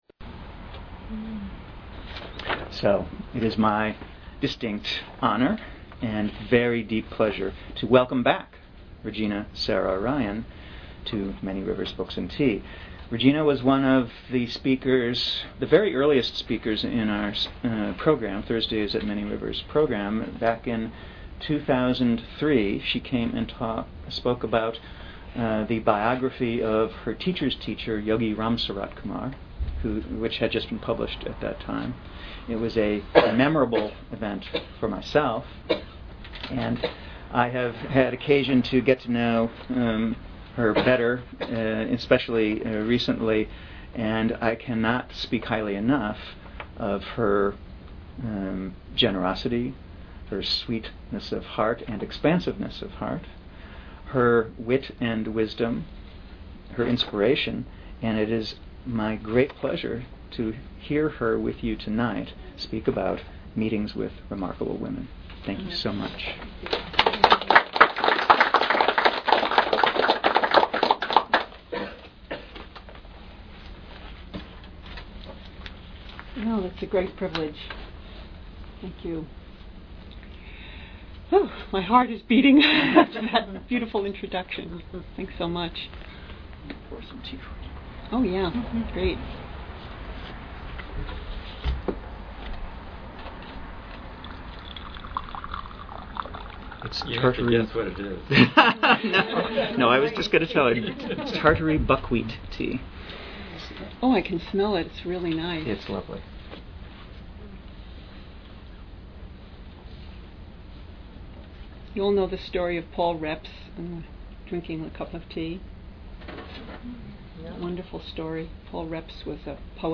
Archive of an event at Sonoma County's largest spiritual bookstore and premium loose leaf tea shop.
Join us for this talk in advance of a weekend seminar designed to inspire participants with the grace and power of the Divine Feminine as She has revealed Herself in deities, great women and men of spirit, friends, mentors and teachers, and within our own hearts.